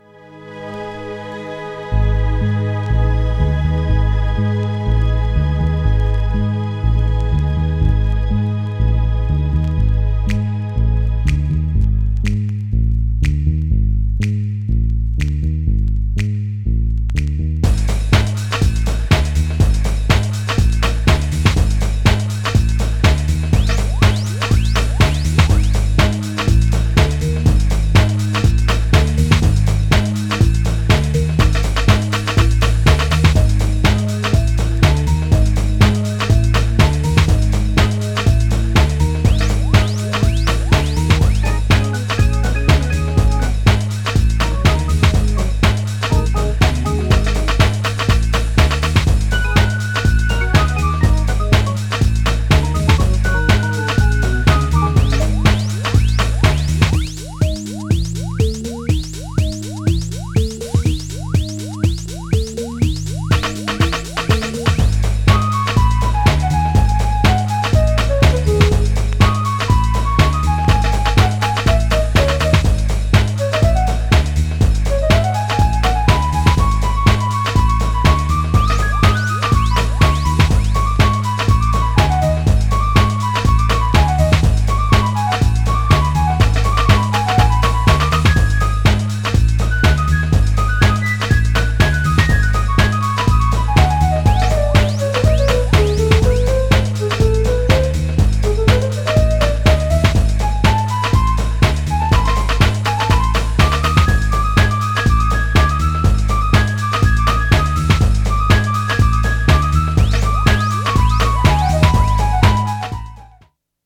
もめっちゃDEEPなINST!!
GENRE House
BPM 121〜125BPM